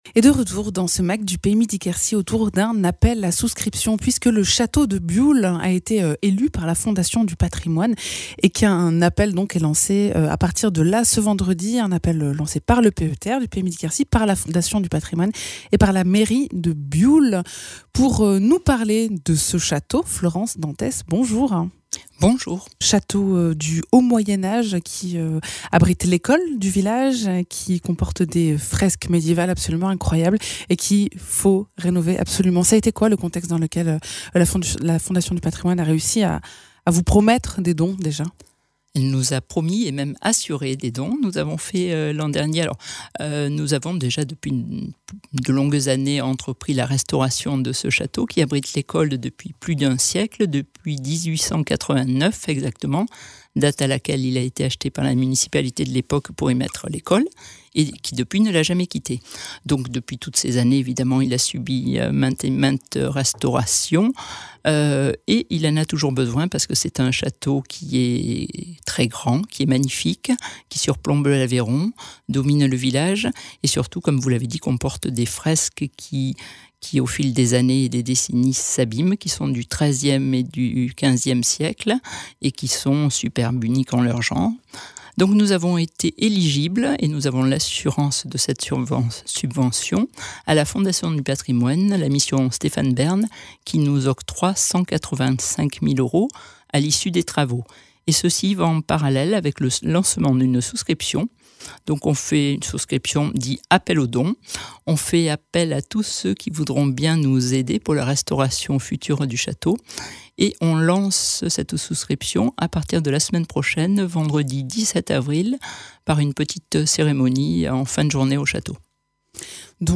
Invité(s) : Florence Danthez, élue à Bioule